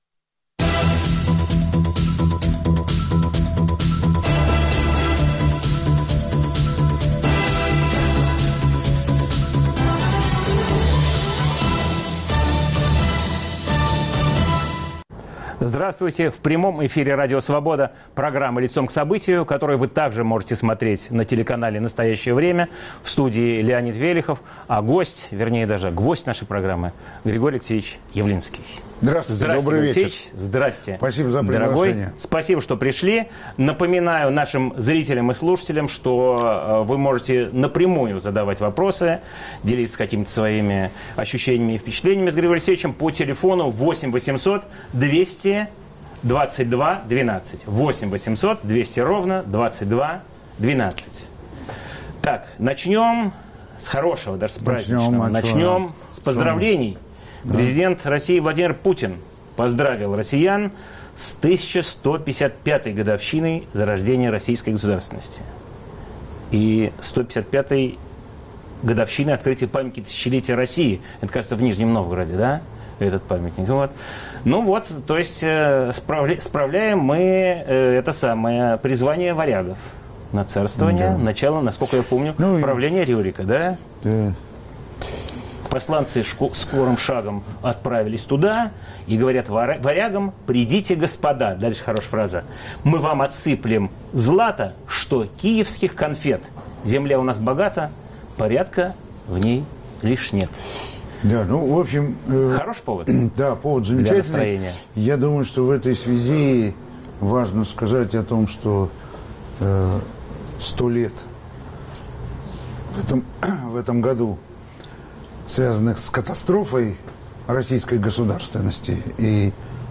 Лидер партии "Яблоко", кандидат в президенты России Григорий Явлинский в эфире Радио Свобода о том, почему без решения внешнеполитических проблем стране не удастся выйти из экономического кризиса.